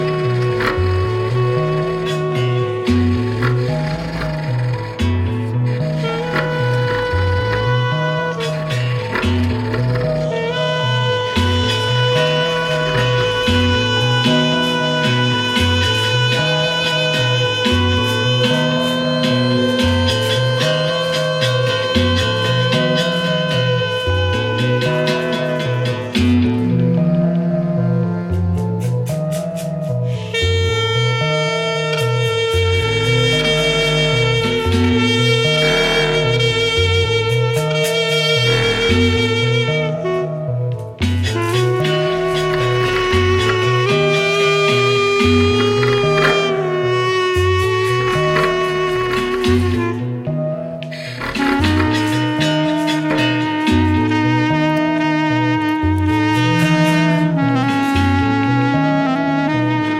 Pharoah Sandersを彷彿とさせる、生々しくタフでありながら優美なサックス。